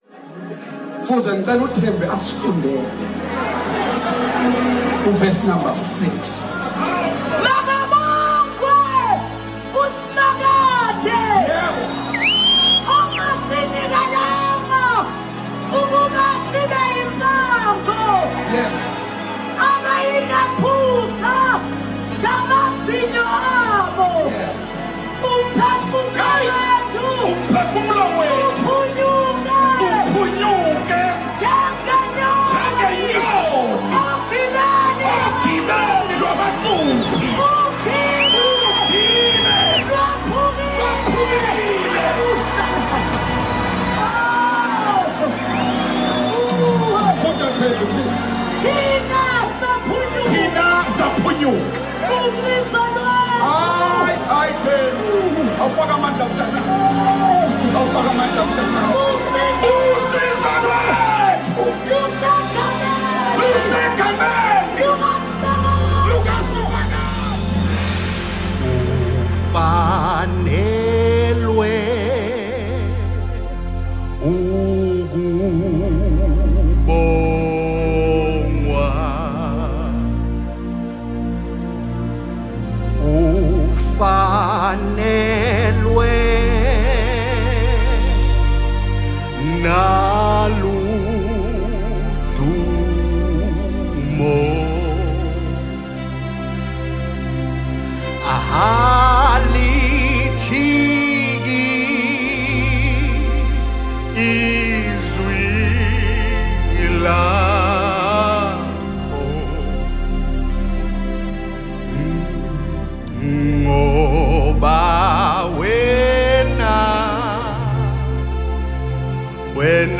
Genre: Gospel/Christian.